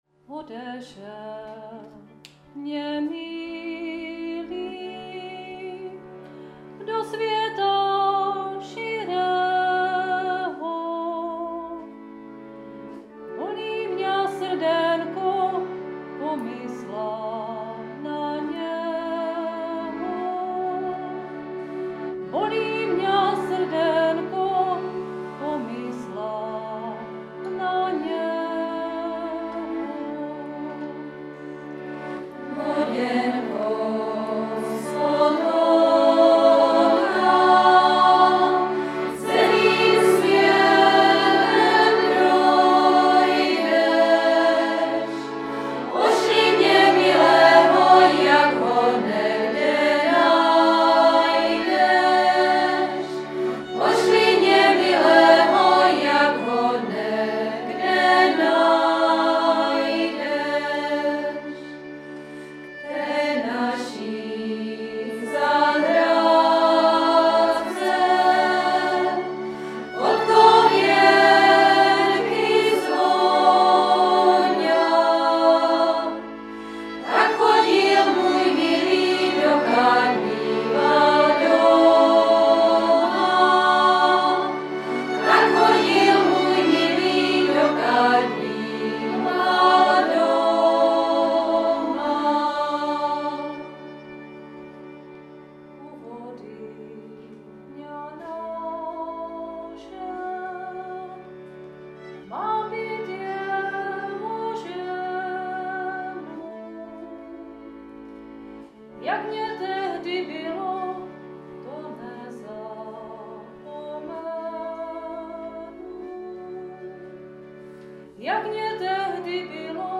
❥ Ženský sboreček Nivnička ♡
cimbál a CM Mladí Burčáci